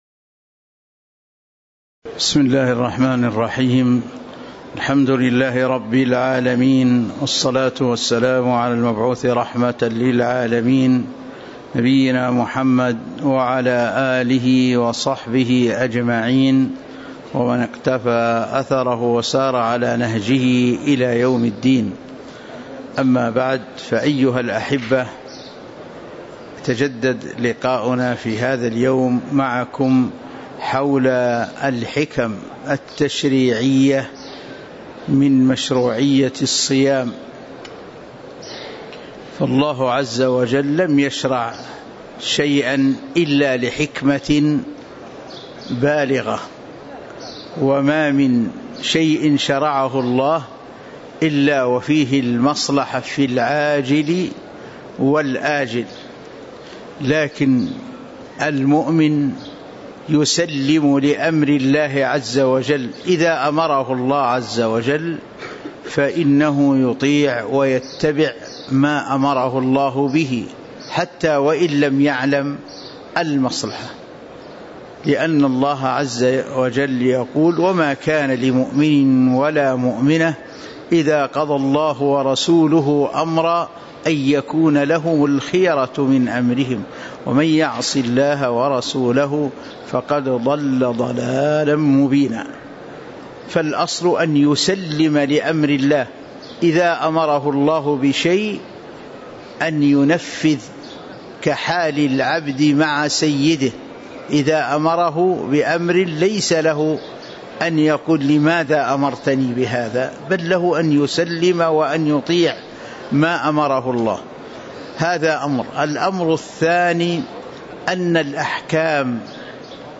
تاريخ النشر ١٠ رمضان ١٤٤٦ هـ المكان: المسجد النبوي الشيخ